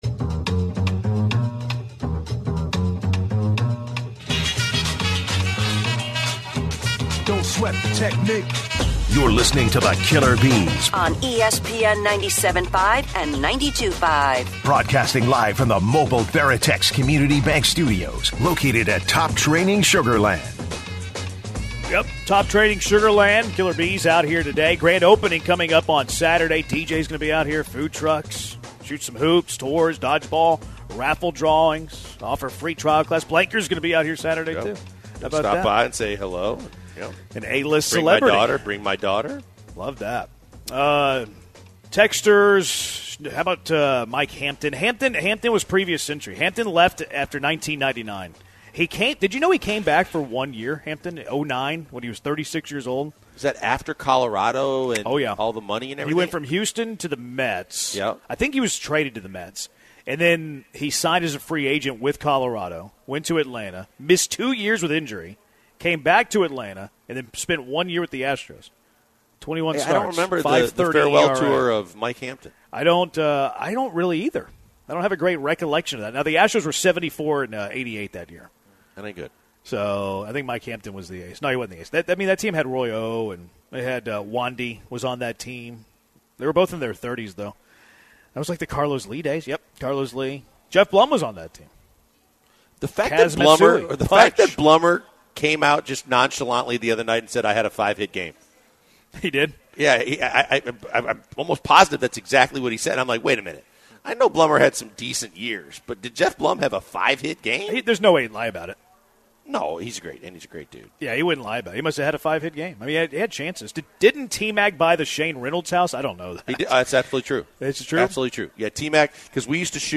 are at Sugarland's Top Training for today's episode!